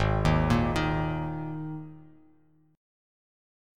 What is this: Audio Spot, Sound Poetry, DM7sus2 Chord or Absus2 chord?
Absus2 chord